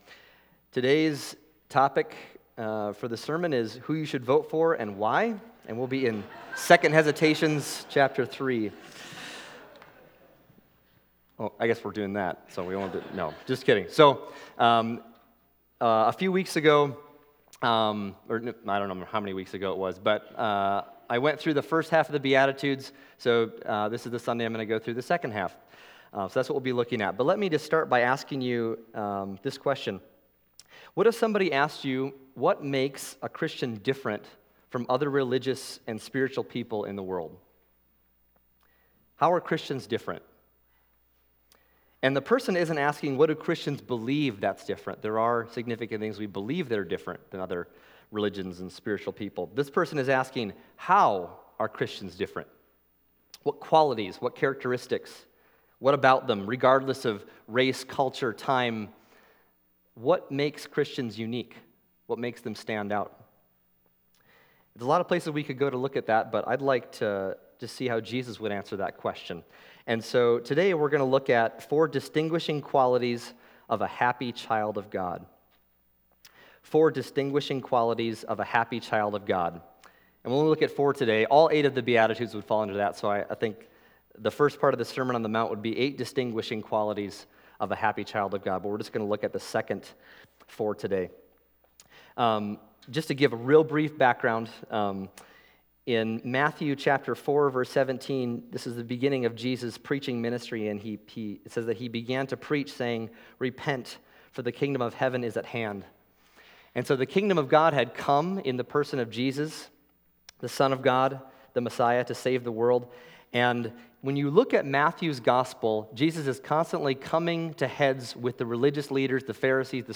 Other Passage: Matthew 5:7-16 Service Type: Sunday Morning Matthew 5:7-16 « Glorify God in Your Body Your Kingdom Come